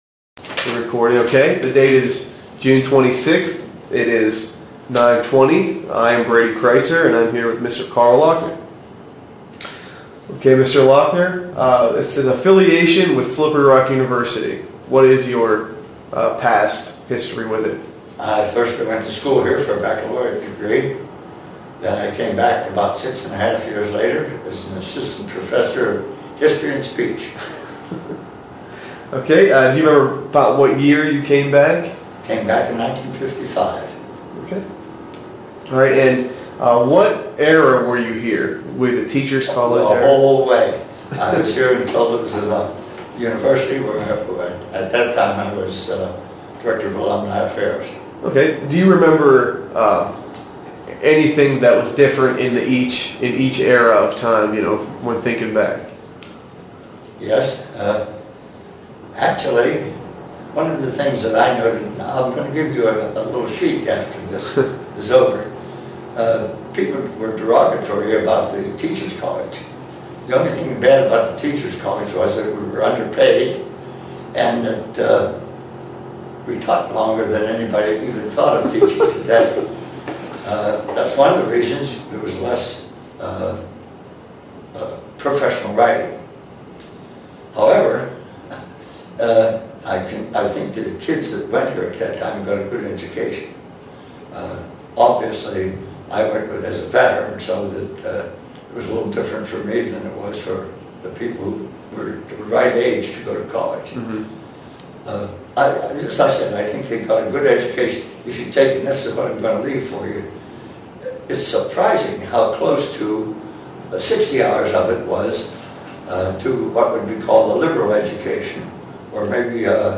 Interview Audio